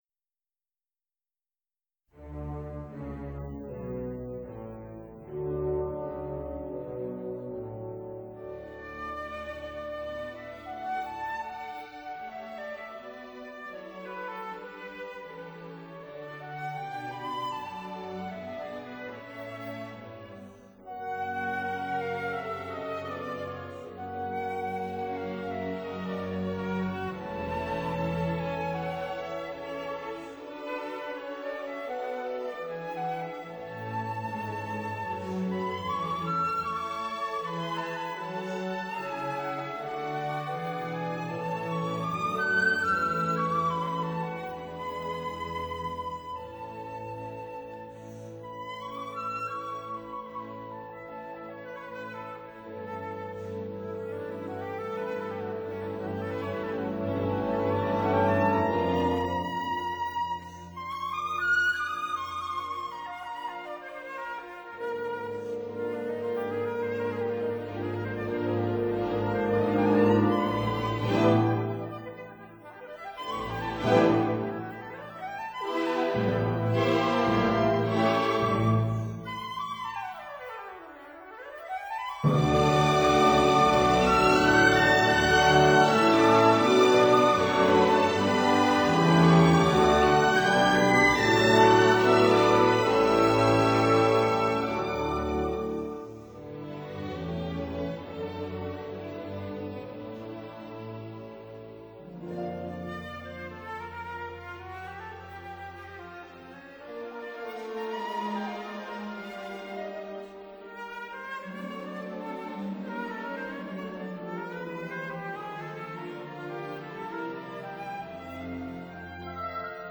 類型： 古典